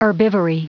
Prononciation du mot herbivory en anglais (fichier audio)
Prononciation du mot : herbivory